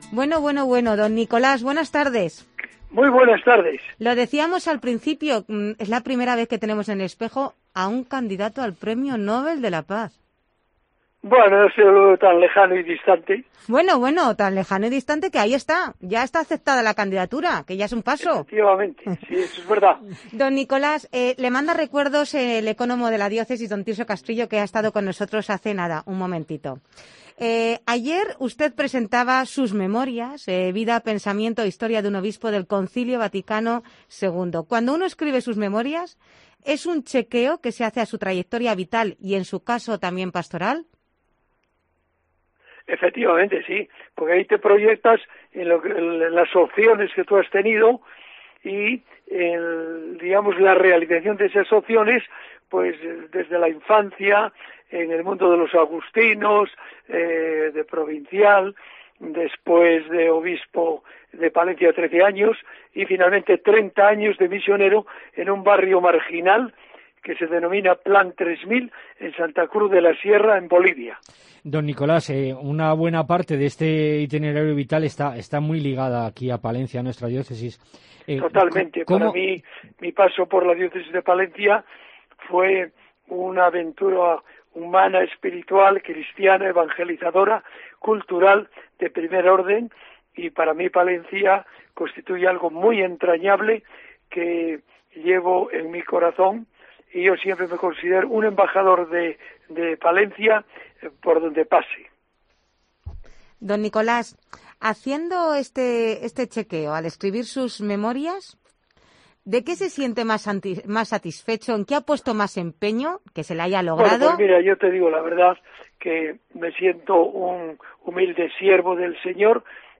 “Lo veo distante y alejado, no me hago ilusiones”, comenta entre risas durante la entrevista concedida este viernes, 5 de noviembre, al 'Espejo' de COPE.